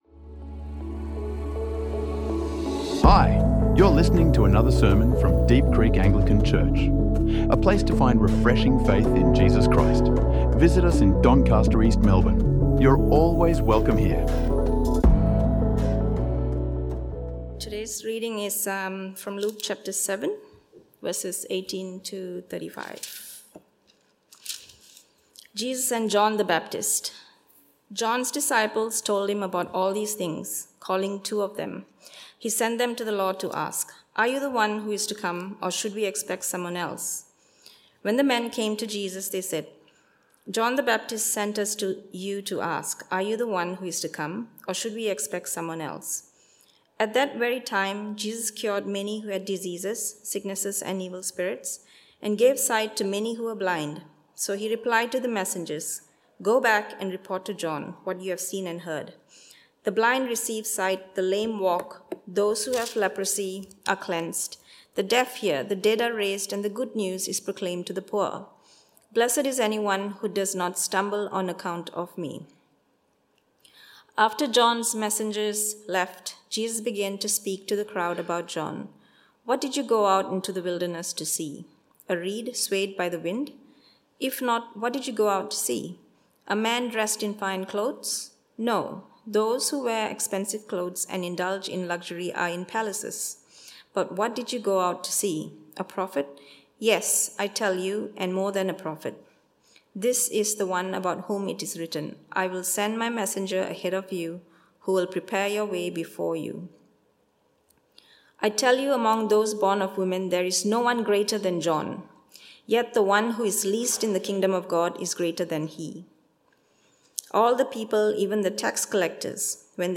John the Baptist asks Jesus a bold question from prison. Discover how doubt, justice, and mercy meet in this powerful sermon on Luke 7:18–35.